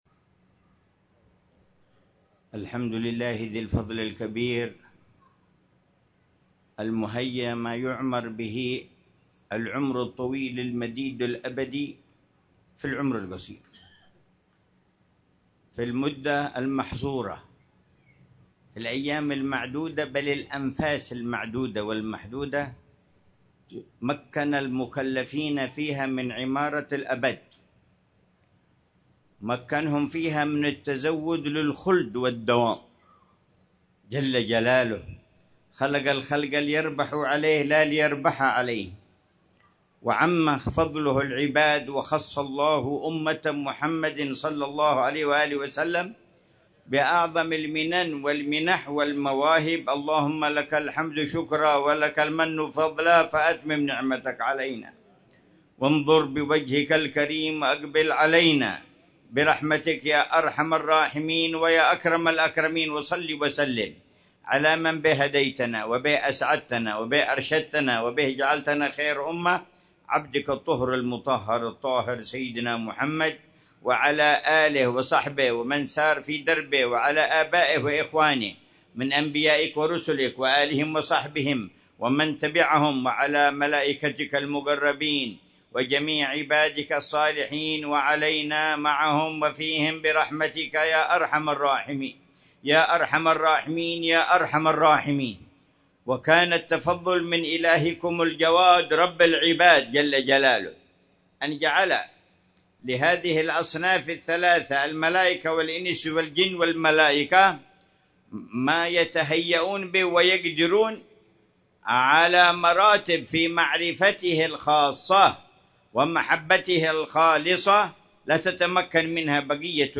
محاضرة العلامة الحبيب عمر بن محمد بن حفيظ في المولد السنوي في دار الابتهاج بمدينة سيئون، حضرموت، ليلة الأربعاء 25 ربيع الأول 1447هـ، بعنوان: